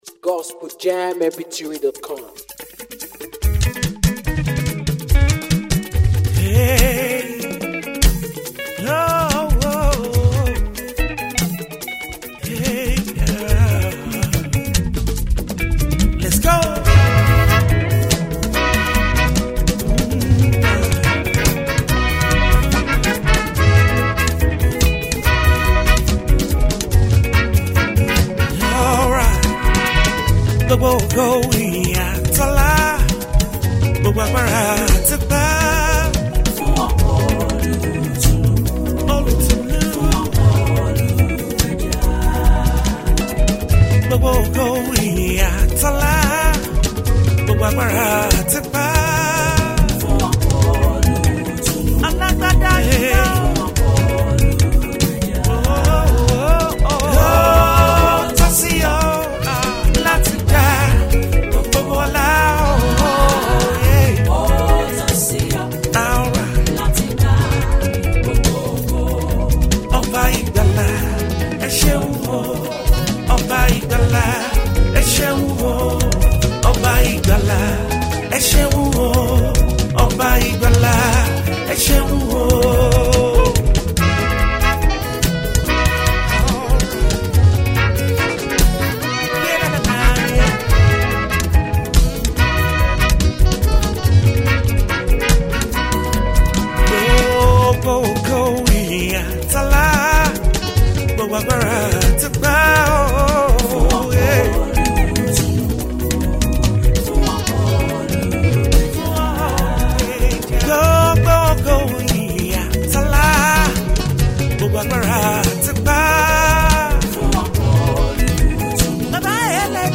traditional African rhythms with contemporary gospel sounds